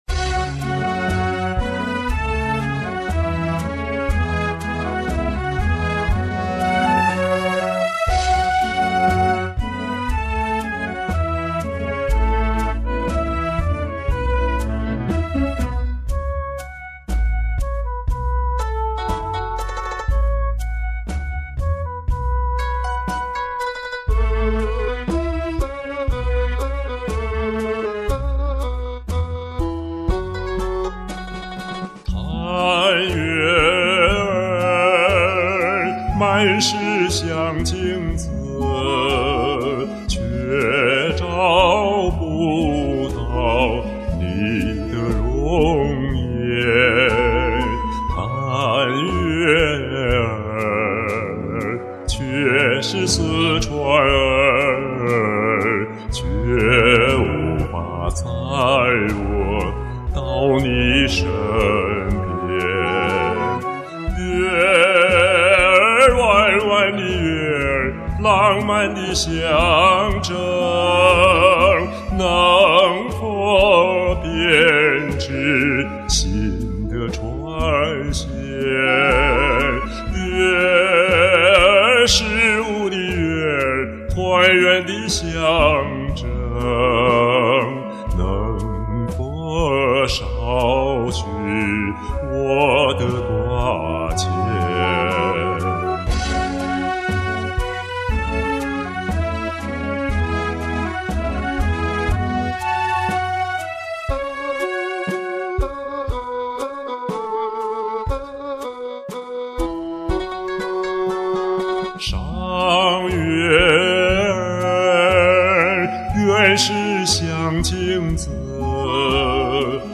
曲調有濃鬱的民歌味道，唱得溫馨深情投入！